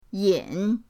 yin3.mp3